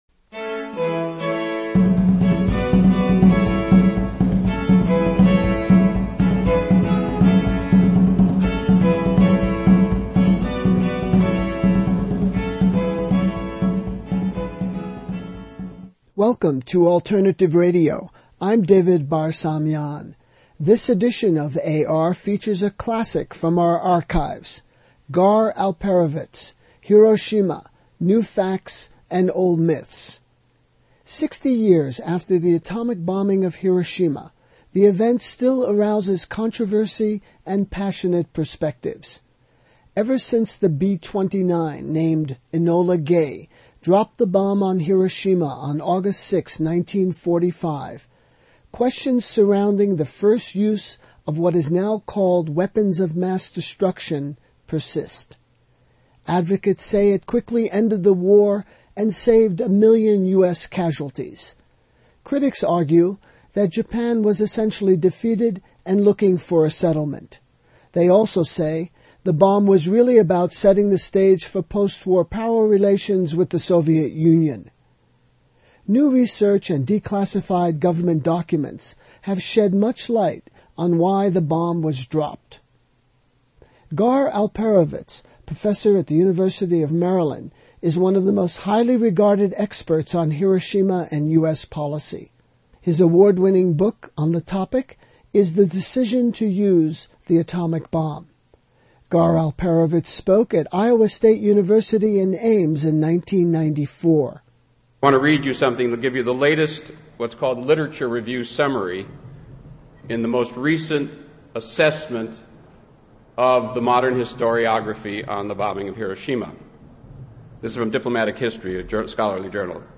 his speech at Iowa State University at Ames (1994)